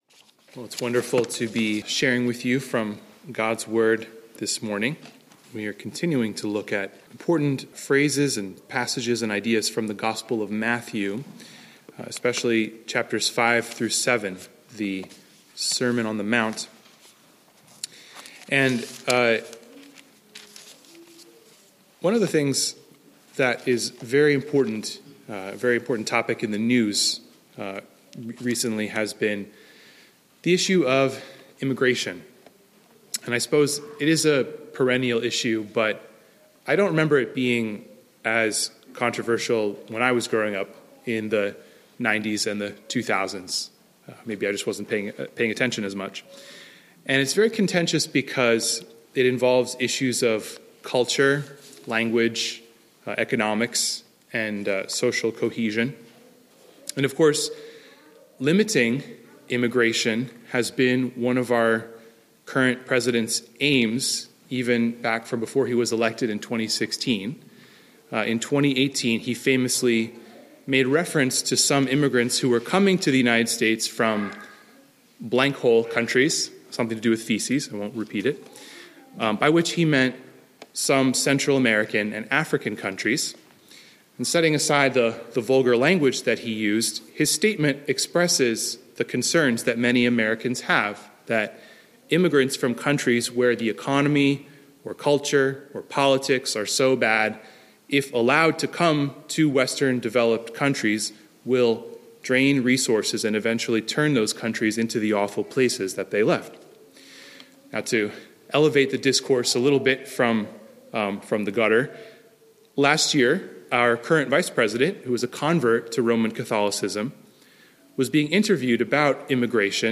Sermon Text: Matthew 5:1, 43–48